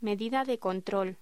Locución: Medida de control
voz